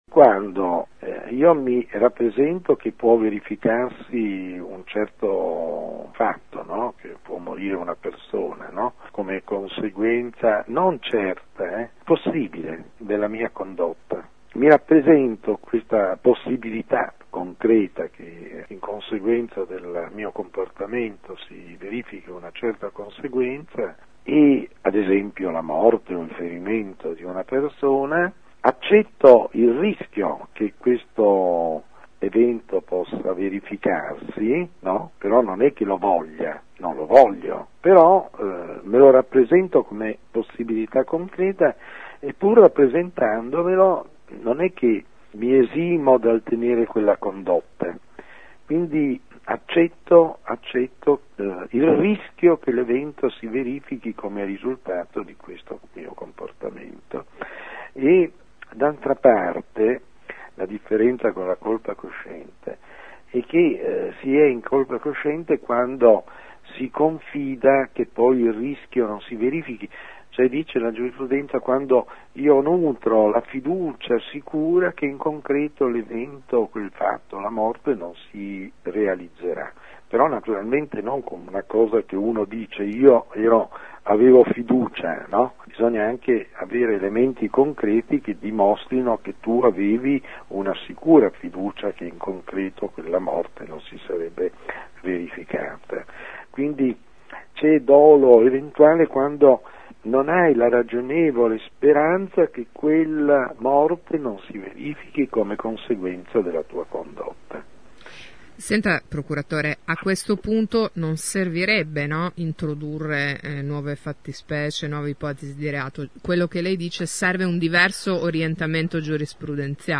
Ascolta il magistrato che parte dalla spiegazione di cosa sia un dolo eventuale